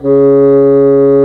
Index of /90_sSampleCDs/Roland L-CDX-03 Disk 1/WND_Bassoons/WND_Bassoon 4
WND CSSN C#3.wav